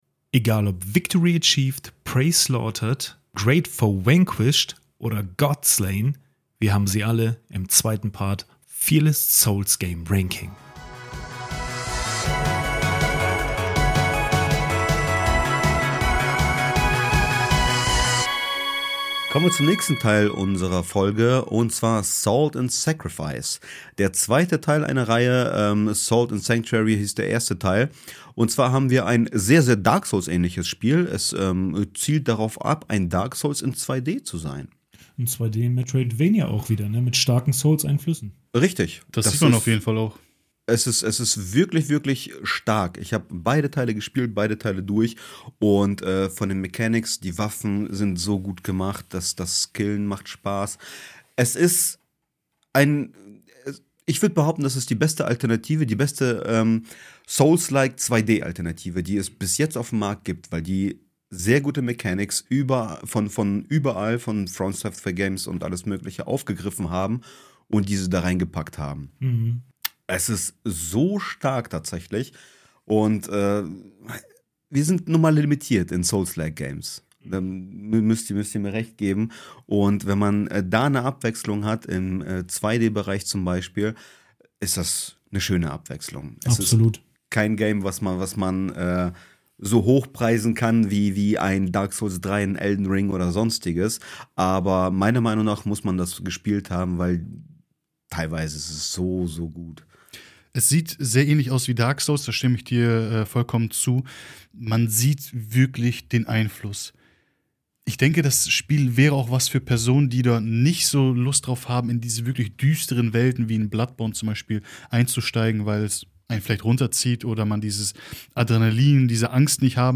*Leider gab es technische Probleme beim Aufnehmen, weshalb die Qualität der Folge nicht so hoch ist wie sonst.